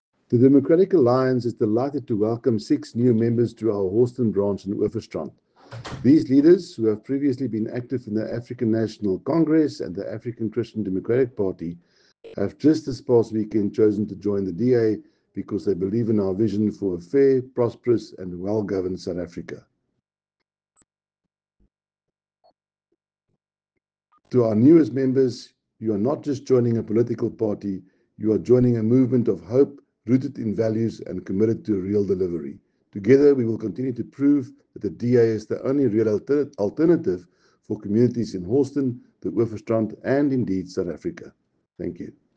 soundbite